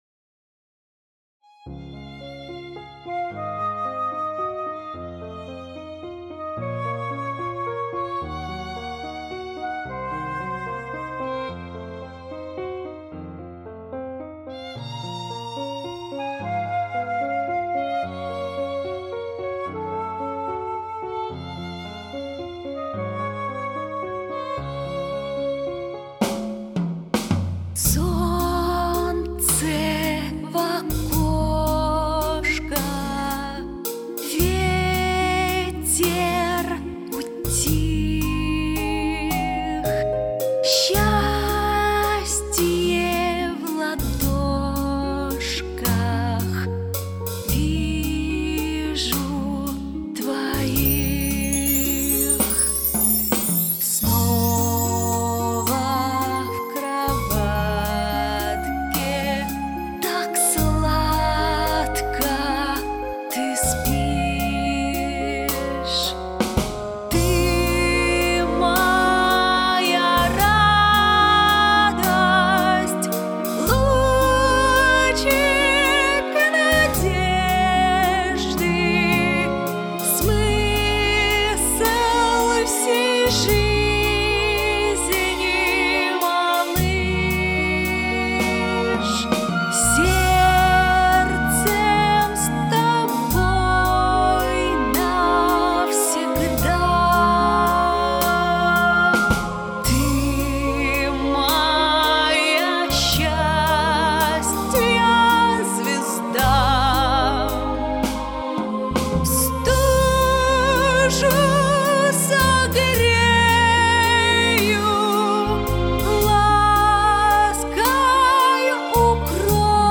Колыбельная Relax Релакс